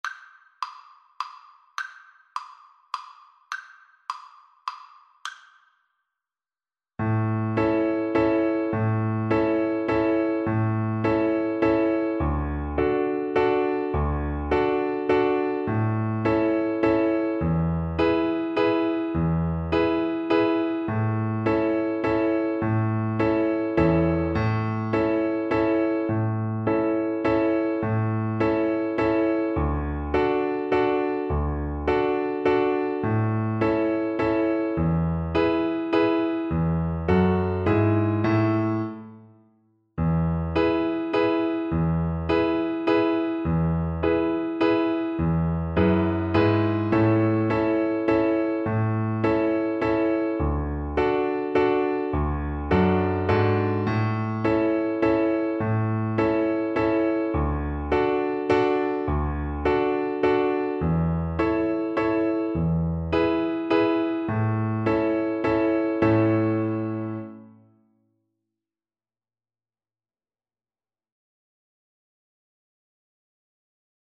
Violin
A major (Sounding Pitch) (View more A major Music for Violin )
3/4 (View more 3/4 Music)
Molto allegro =c.144
Traditional (View more Traditional Violin Music)
Irish